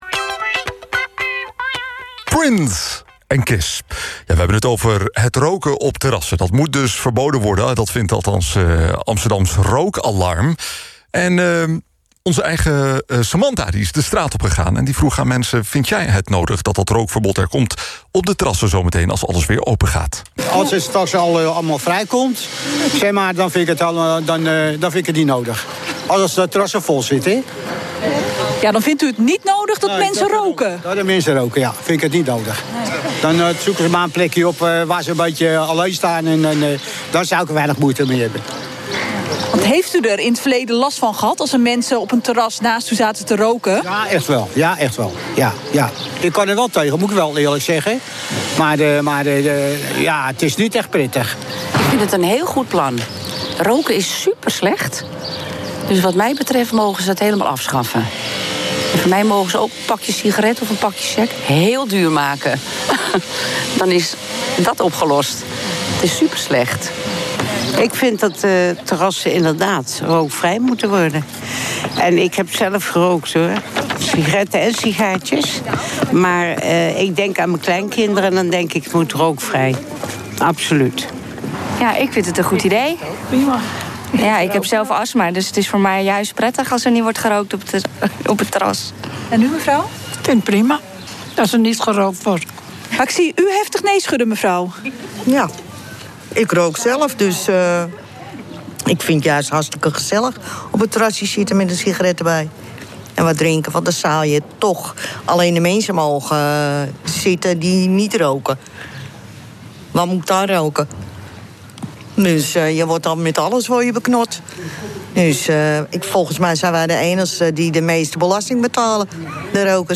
Interview 1 Interview 2 Categorieën Nieuws Artsen pleiten voor rookvrije terrassen bij heropening Op weg naar rookvrij: Nieuw-Zeeland wil sigarettenverbod voor iedereen geboren na 2004